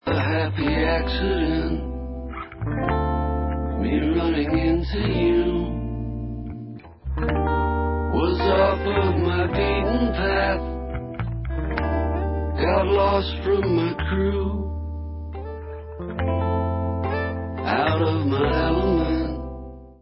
indie-rocková kapela